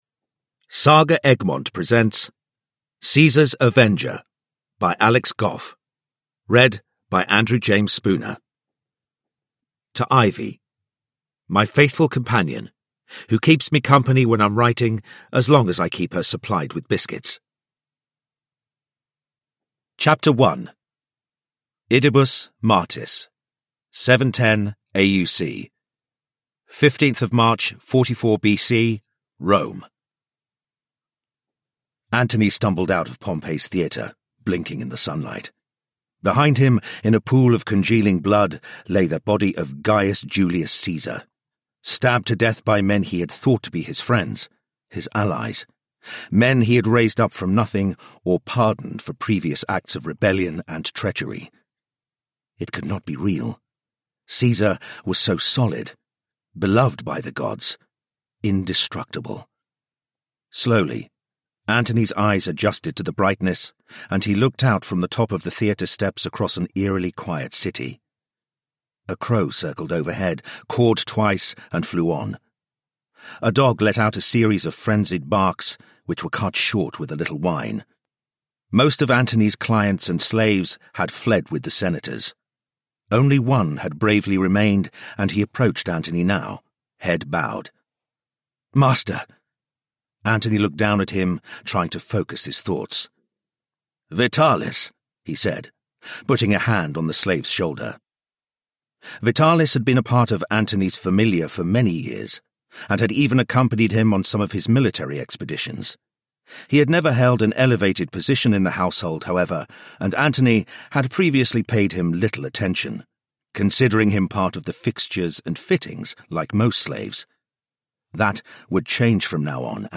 Caesar's Avenger – Ljudbok